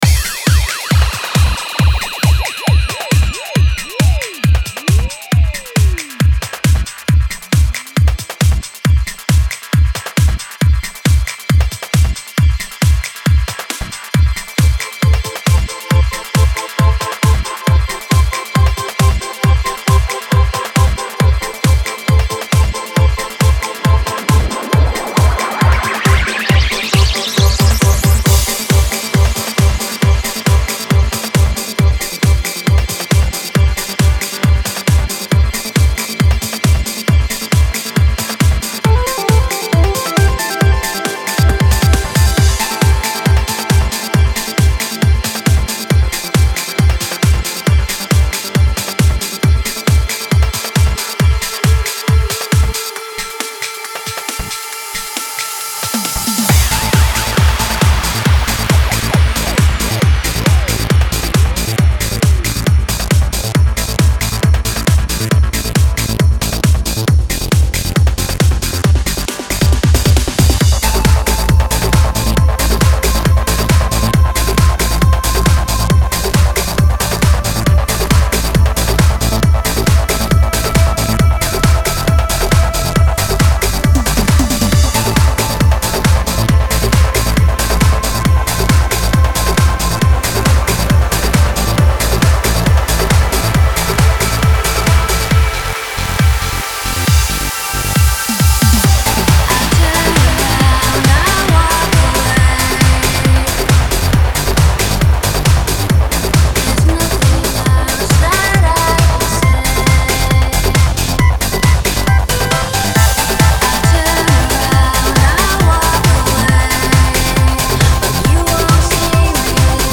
The issue he is seeing or hearing is there is a sort of glitch between each track. It sounds almost like a sloppy transition between songs.